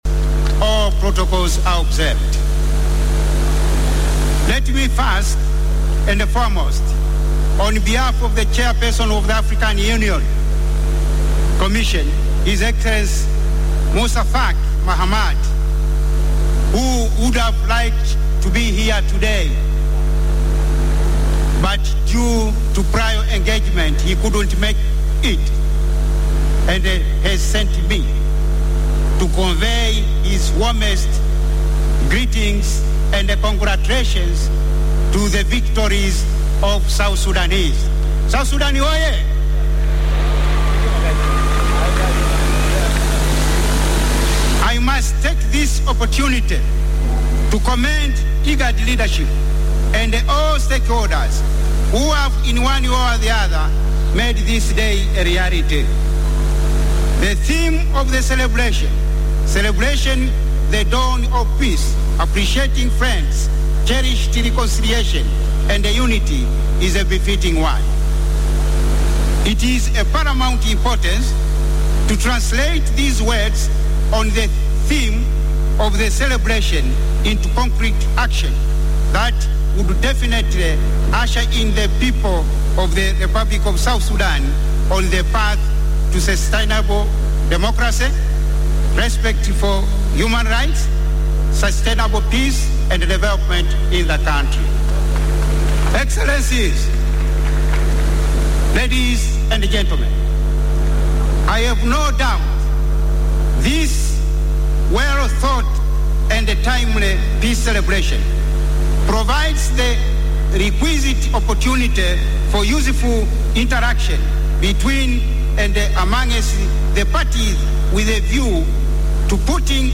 Peace Celebration: Speech by AU Ambassador Joram Biswaro
Addressing a gathering in Juba, Ambassador Biswaro said it is time to walk the talk and the leaders must commit to bringing peace and stability in South Sudan.